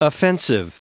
Prononciation du mot offensive en anglais (fichier audio)
Prononciation du mot : offensive